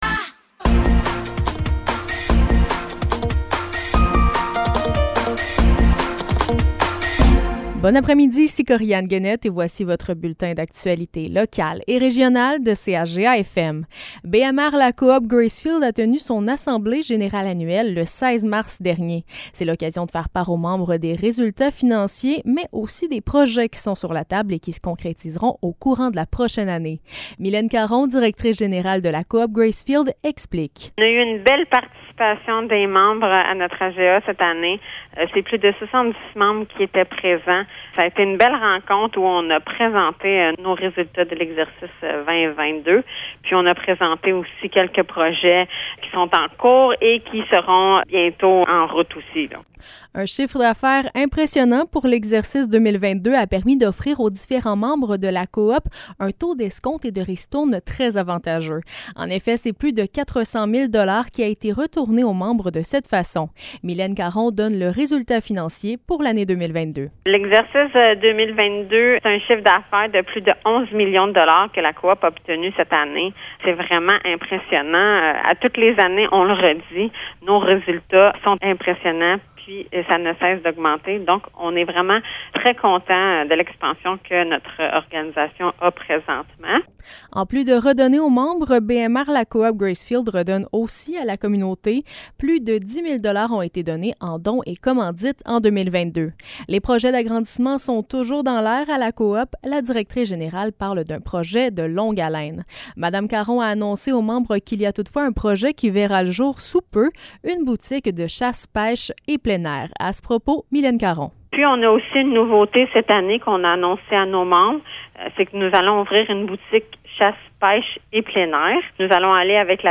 Nouvelles locales - 27 mars 2023 - 15 h